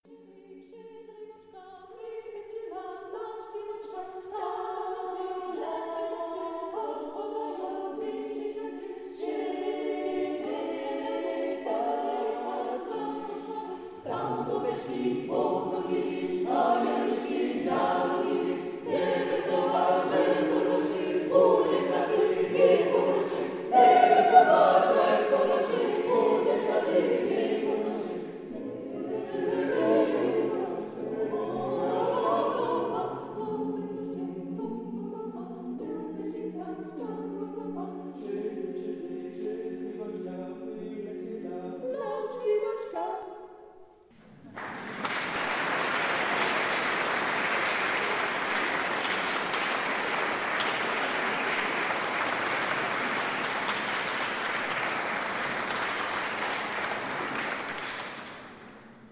Musique chorale des pays de l'Est
Extraits de la restitution de fin de stage
dans l'église de Saint-Hugues de Chartreuse, Musée Arcabas, le 4 juillet 2023